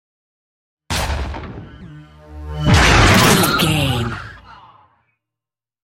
Double hit with whoosh sci fi
Sound Effects
futuristic
intense
woosh to hit